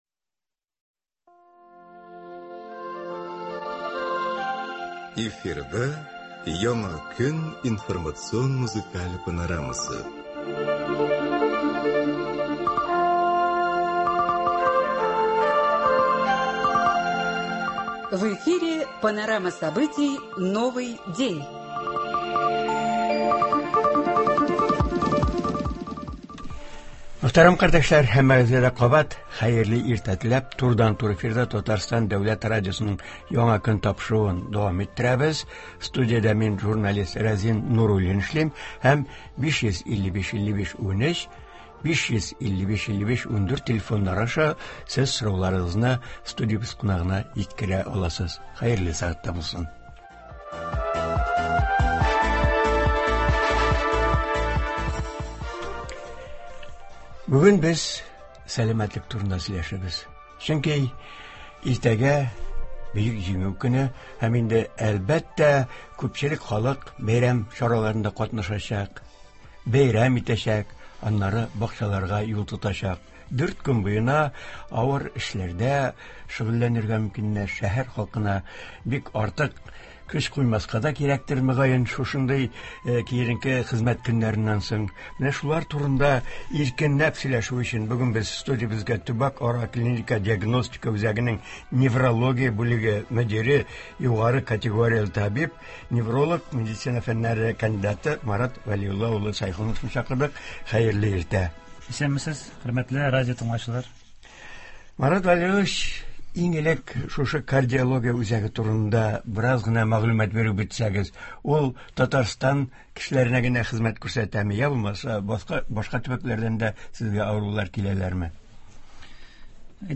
Туры эфир (08.05.24)